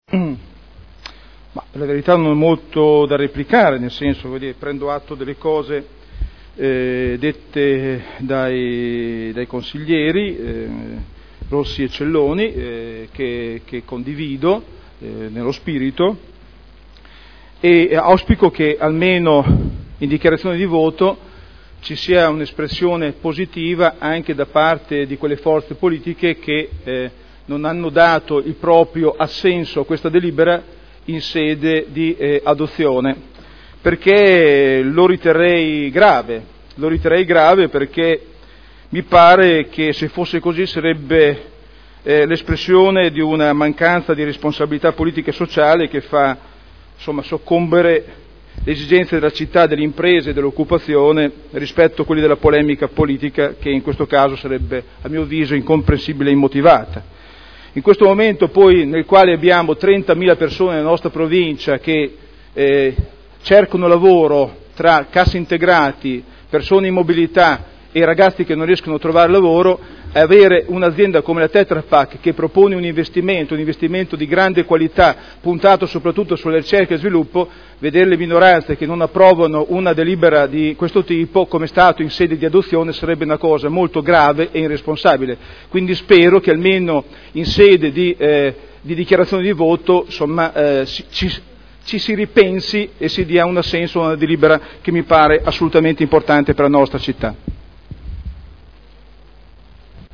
Seduta del 30/05/2011. Dibattito su proposta di deliberazione: Variante al POC-RUE – Area in via Emilia Ovest – Z.E. 1481-1502 – Approvazione